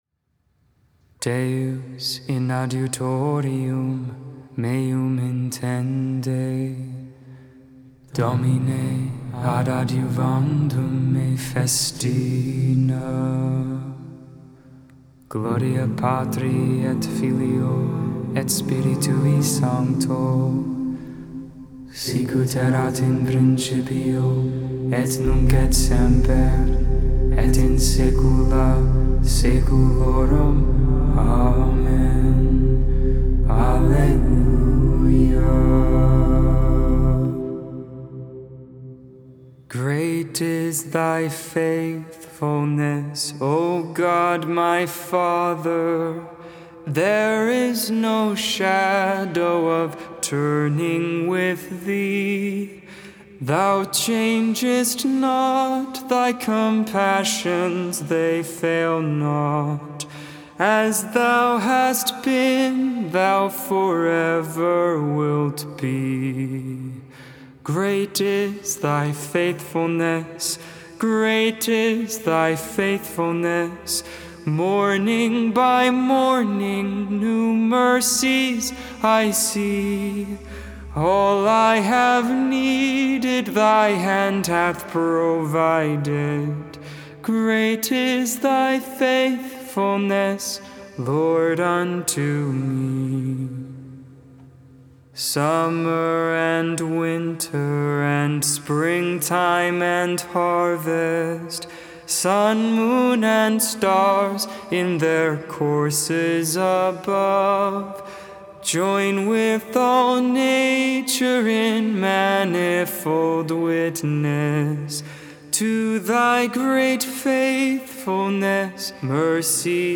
5.15.21 Vespers, Saturday Evening Prayer